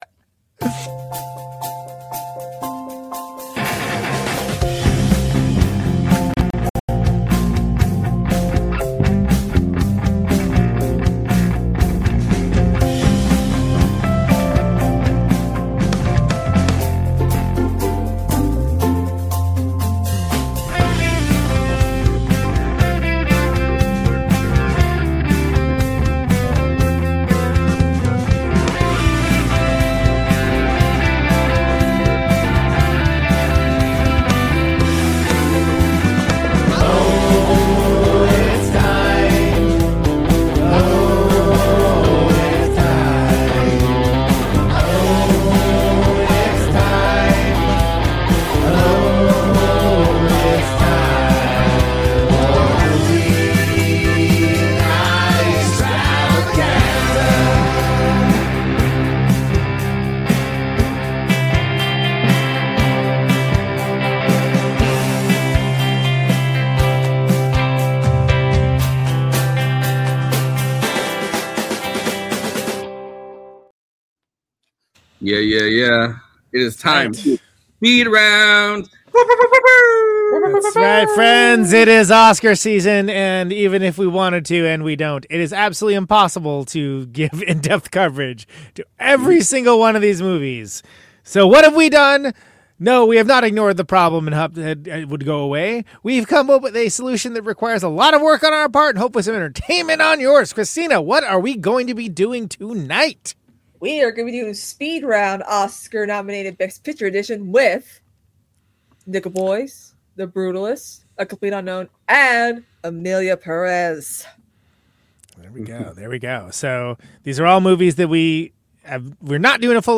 Movie Night Extravaganza is an irreverent, quick moving show about movies.